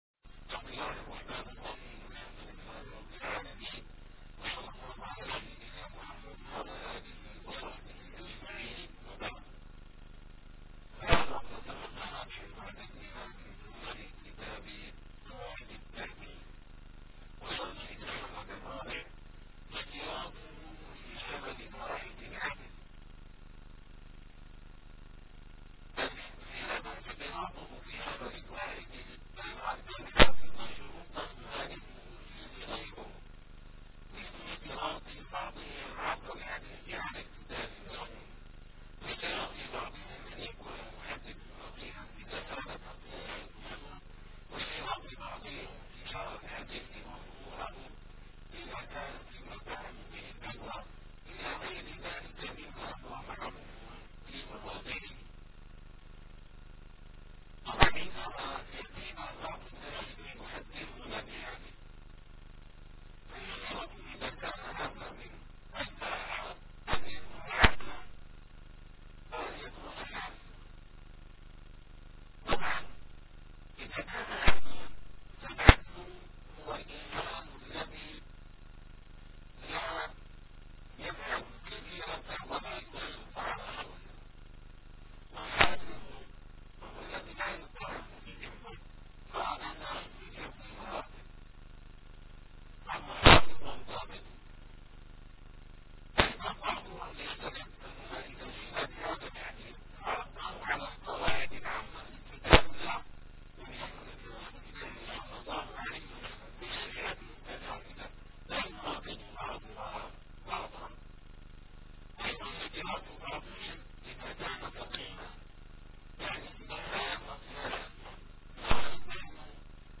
- الدروس العلمية - قواعد التحديث من فنون مصطلح الحديث - 107- السبب الرابع ص392